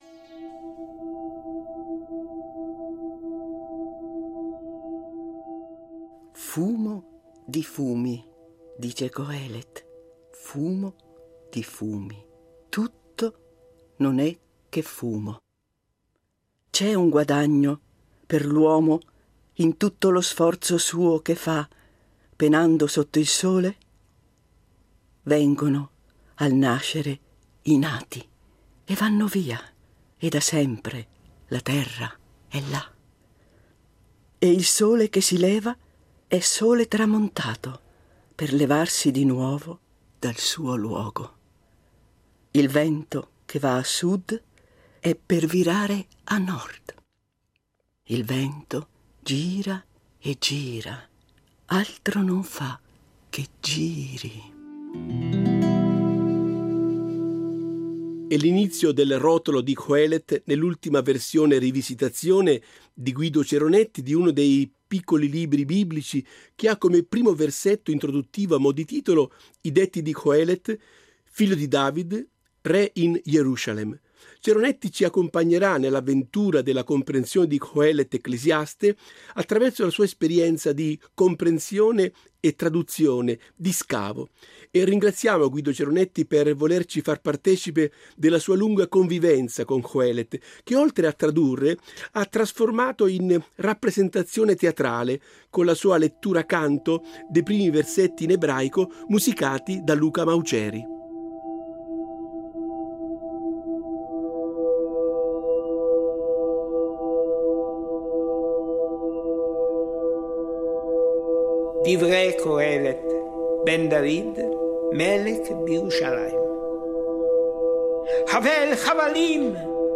Guido Ceronetti legge l’Ecclesiaste